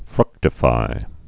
(frŭktə-fī, frk-)